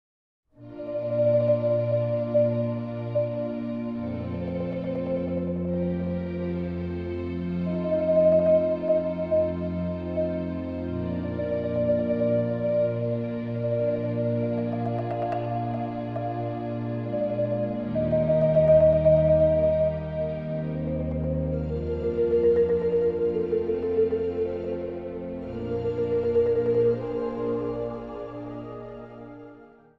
Instrumentaal | Marimba
Instrumentaal | Synthesizer
Instrumentaal | Trompet
Instrumentaal | Viool